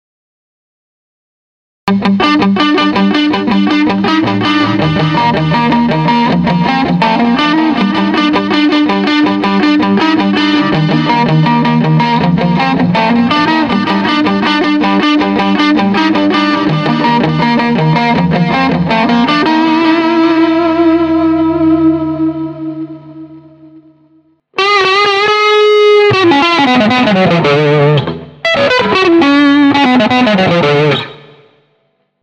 Вниз  Играем на гитаре